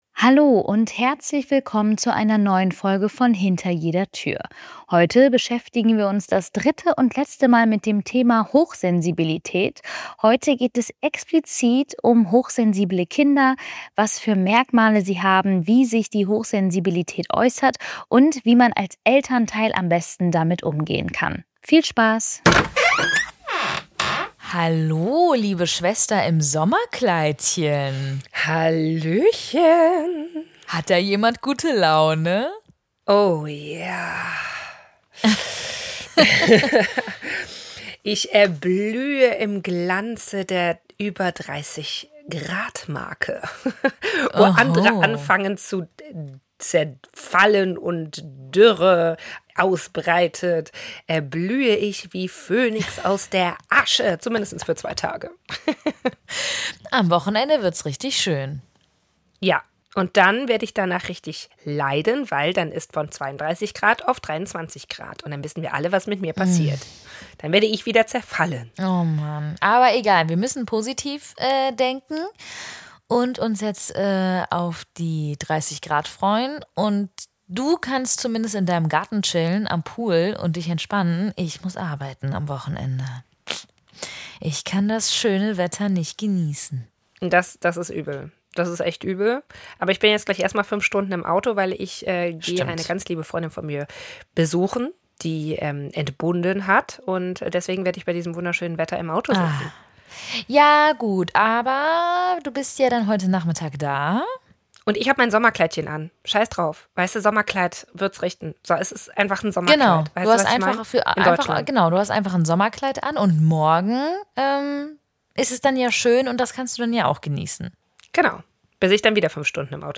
Beschreibung vor 9 Monaten Folge beginnt bei: 5:43 Min In der dritten und abschließenden Folge der kleinen Podcastreihe steht die Hypersensibilität im Kindesalter im Fokus. Die Schwestern sprechen darüber, wie sich Hochsensibilität bei Kindern äußern kann, worauf Eltern, Fachkräfte und Bezugspersonen achten sollten und welche Begleiterscheinungen häufig auftreten.
Wir sprechen offen und locker über diese Themen - gegen Stigmatisierung und für Offenheit und Toleranz.